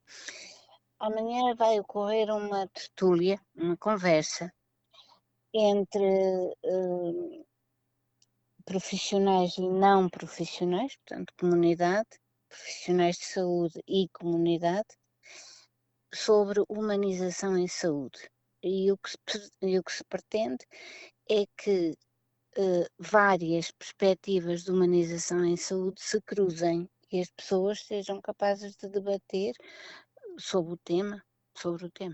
Liseta Goncalves presidente da Comissão Nacional de Cuidados Paliativos destaca que o tema “Humanização em Saúde, Aproximar Profissionais e Comunidade”, este ciclo pretende fomentar a reflexão, o diálogo e a partilha de experiências entre profissionais de saúde e população, reforçando práticas mais humanas, empáticas e centradas no utente: